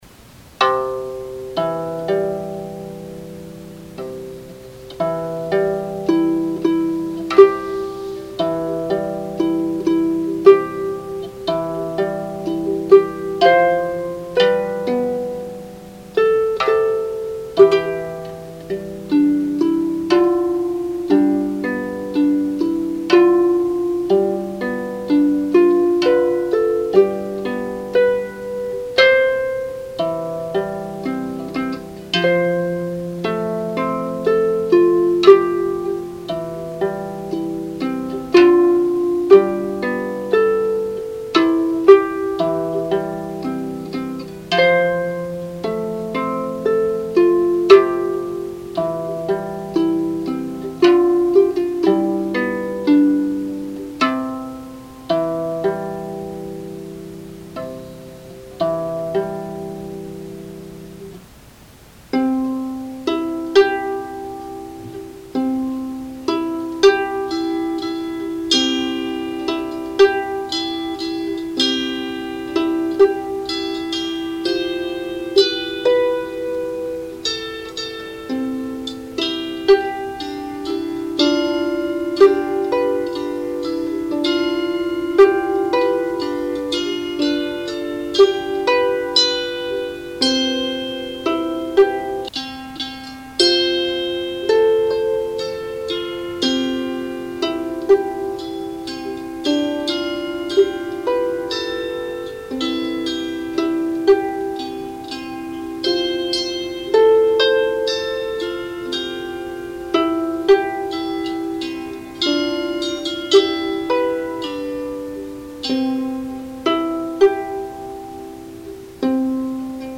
This arrangement is an experiment, in which I play the lyre and zither at the same time, in real-time. My left hand plays the bass notes, or accompaniment, on lyre, and my right hand plays the melody on zither.
In this arrangement, I first play a standard accompaniment on lyre.
The second time through, I play the lyre accompaniment up an octave, with my right hand on zither, playing the melody in the same octave.
Finally, you’ll hear the zither arrangement with bass and melody.
brahms-lyre_and_zither.mp3